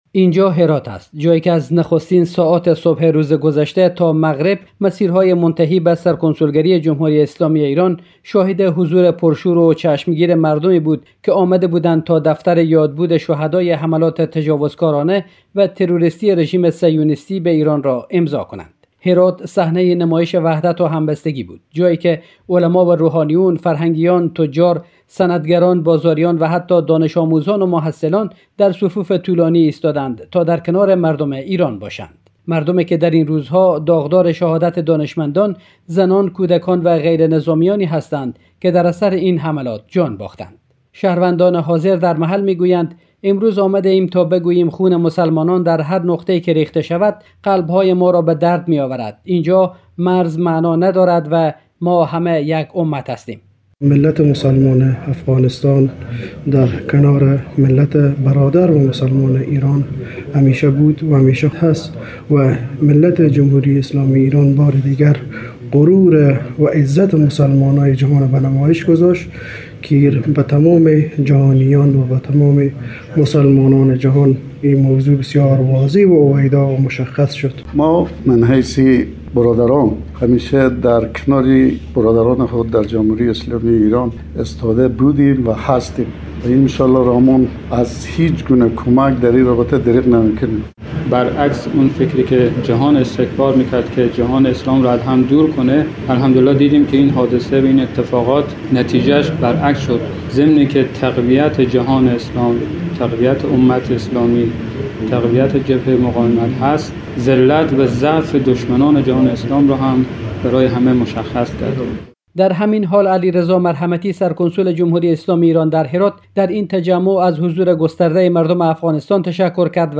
خبرنگار رادیو دری